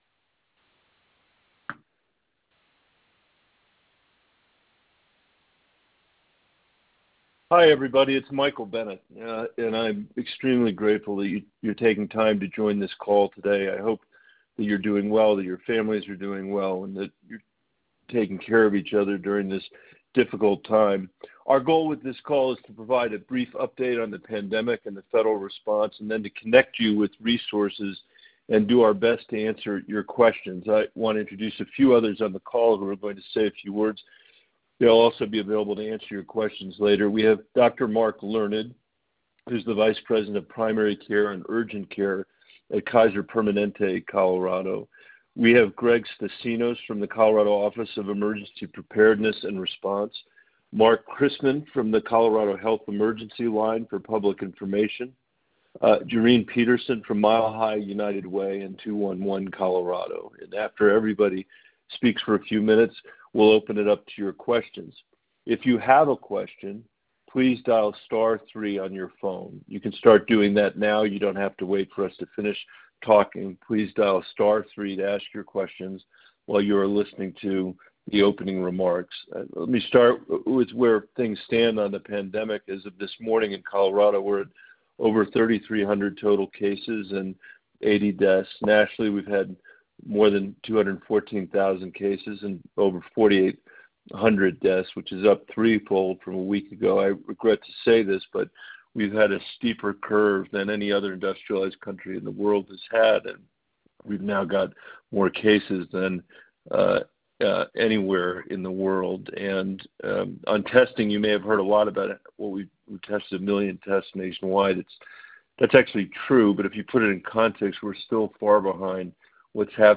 AUDIO: Bennet Holds Coronavirus Telephone Town Hall with Coloradans - U.S. Senator Michael Bennet
Denver – Today, Colorado U.S. Senator Michael Bennet held a telephone town hall where he provided Coloradans with an update on the federal response to the Coronavirus Disease 2019 (COVID-19) and answered questions from participants.